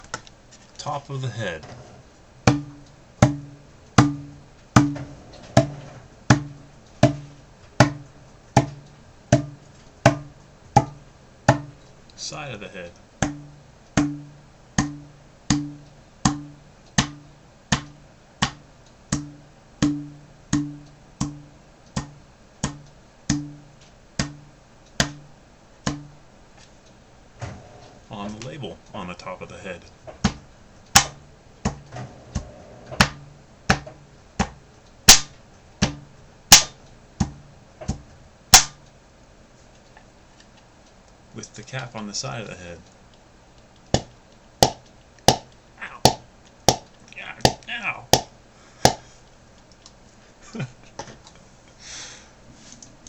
2-liter Sprite bottle hitting the top and side of my head
Category ⚡ Sound Effects
2-liter bonk bottle container effect head hit hollow sound effect free sound royalty free Sound Effects